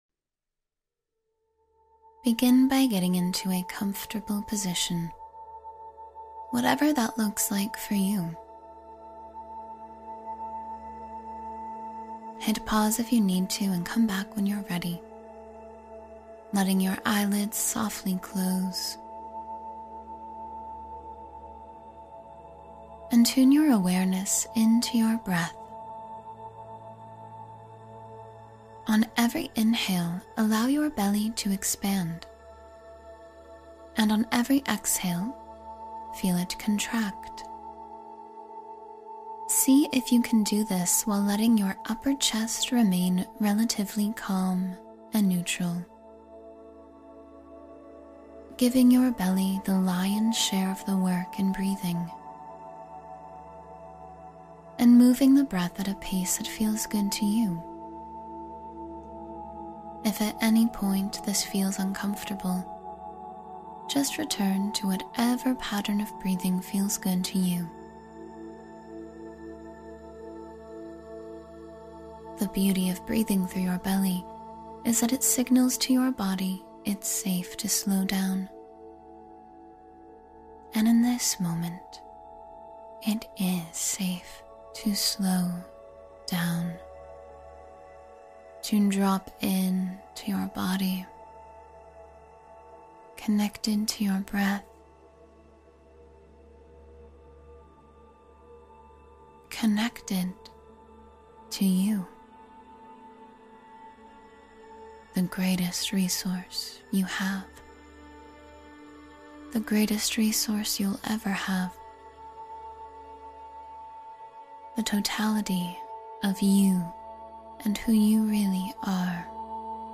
The Gentle Strength of Loving Yourself — Meditation for Self-Compassion